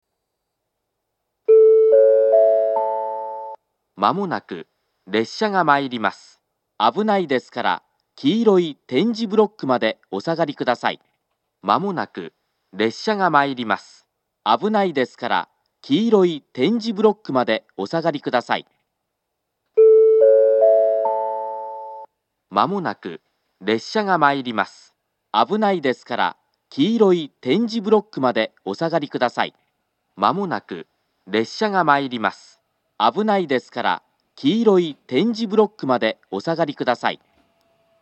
３番線接近放送